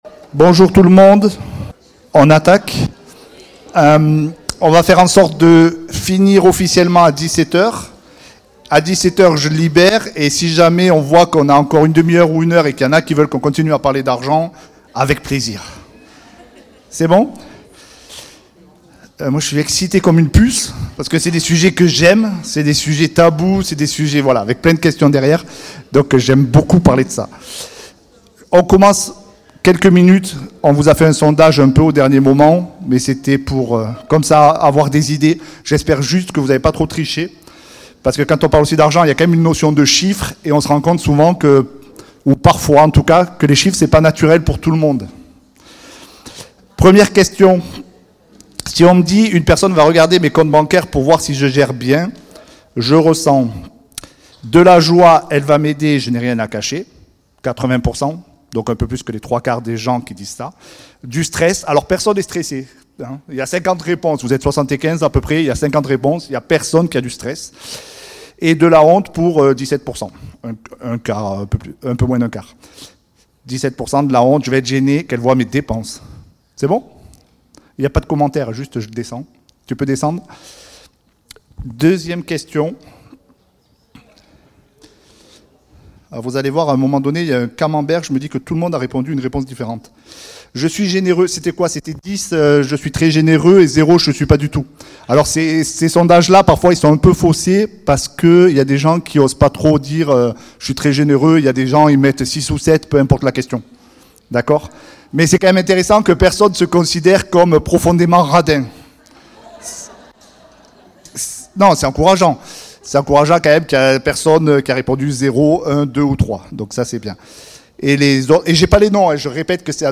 Type De Service: ACBM CAMPUS Sujets: Session 6 : Histoire de l'Église au Moyen-Âge et les reformes - Questionnaire sur l'argent